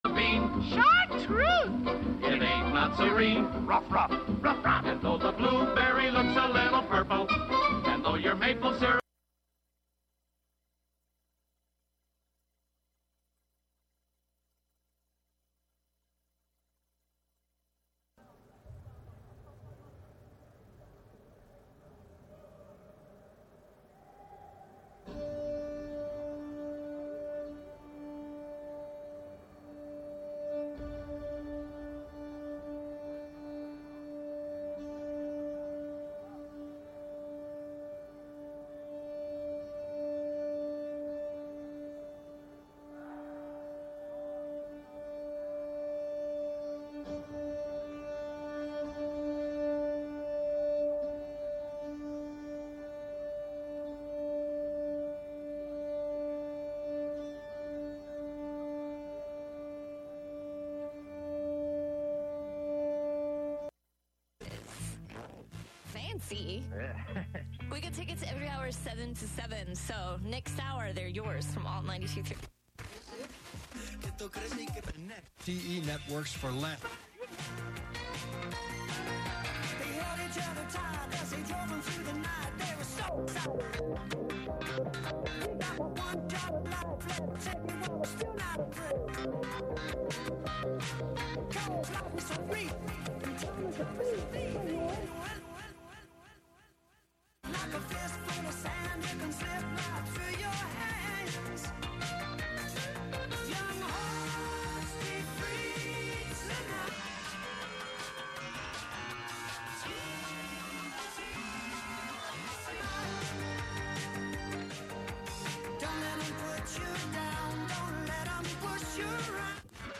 Live from Brooklyn, NY
instant techno